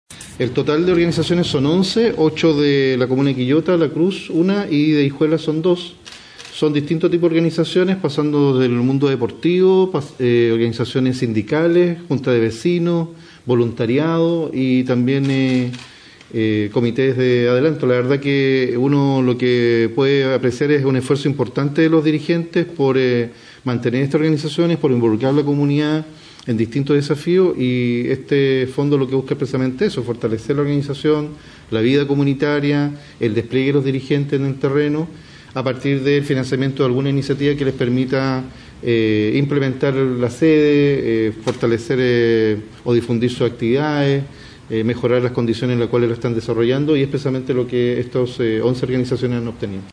El gobernador de Quillota, César Barra, dijo que este programa es parte de los compromisos de la Presidenta para fortalecer las organizaciones sociales en el territorio.
fondo-social-provincia-quillota-gobernador-barra-fondo-social_01.mp3